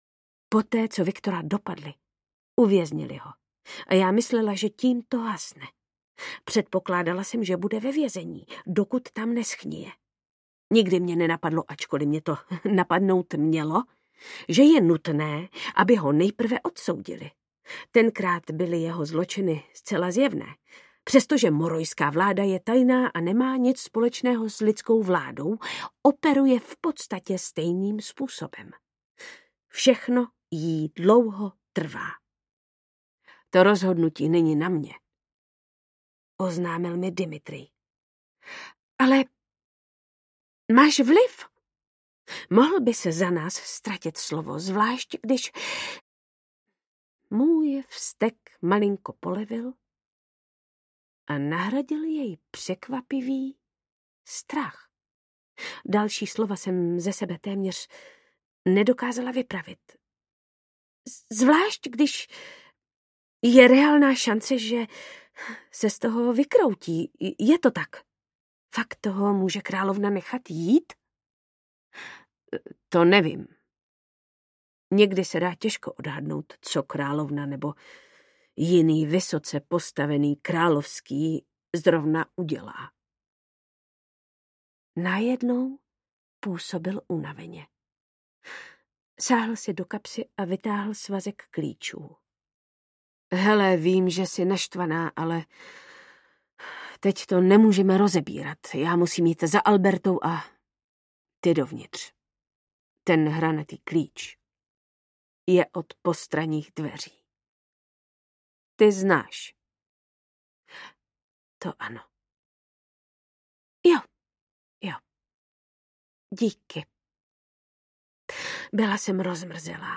Stínem políbená audiokniha
Ukázka z knihy
Vyrobilo studio Soundguru.